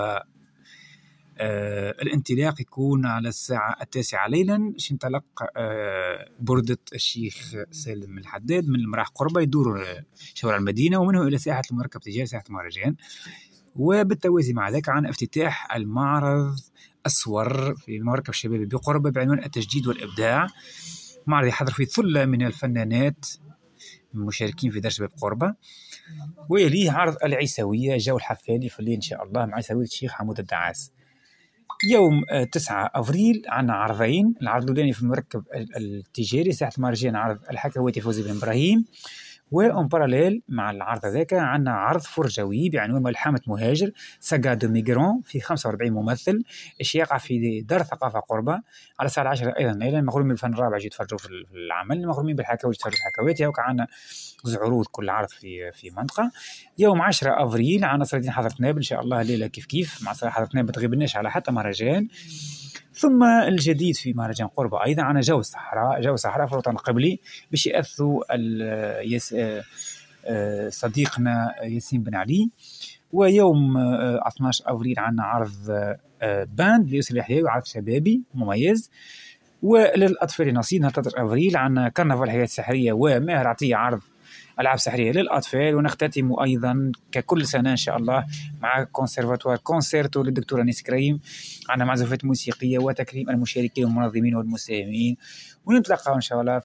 نابل: تفاصيل برمجة مهرجان ليالي مدينة قربة(تصريح )